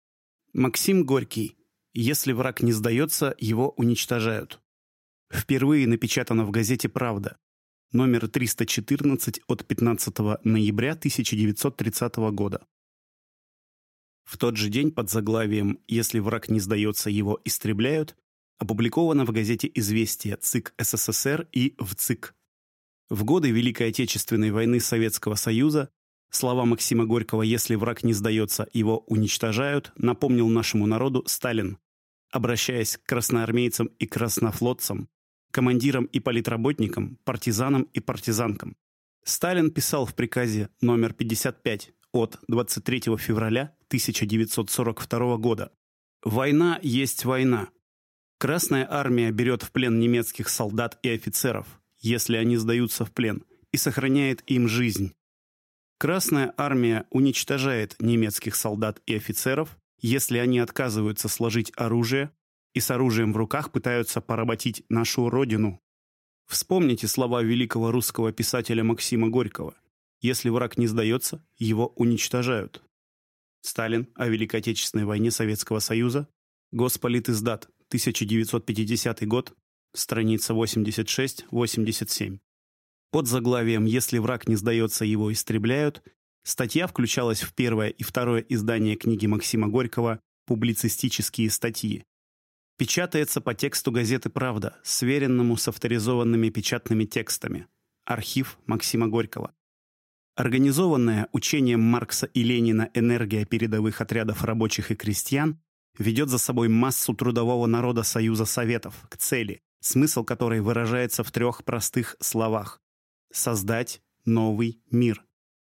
Аудиокнига Если враг не сдаётся, – его уничтожают | Библиотека аудиокниг